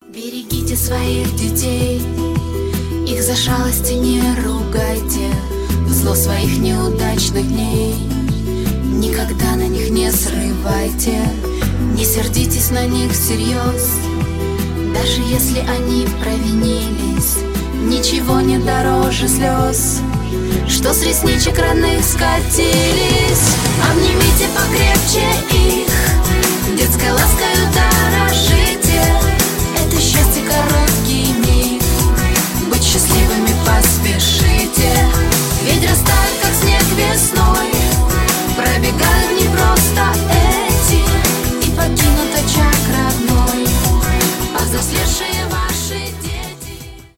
поп , серьезные , детские , душевные